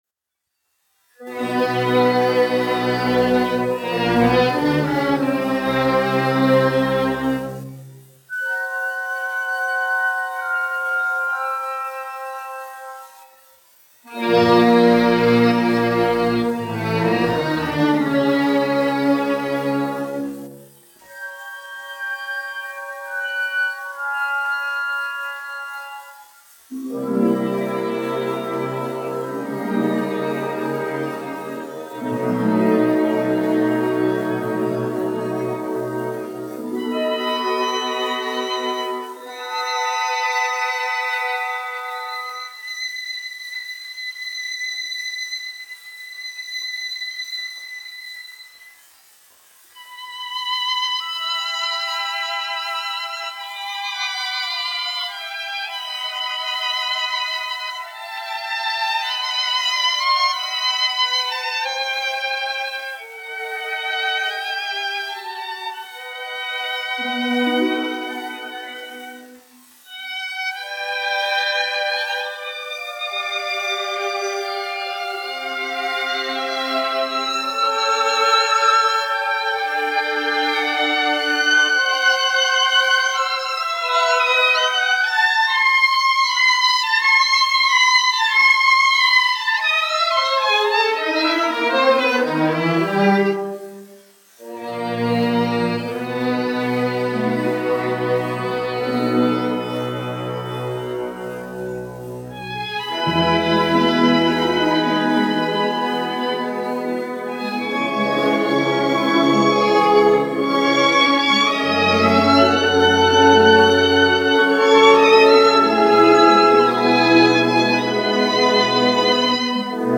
Staatskapelle Berlin, izpildītājs
1 skpl. : analogs, 78 apgr/min, mono ; 25 cm
Operas--Fragmenti
Orķestra mūzika
Skaņuplate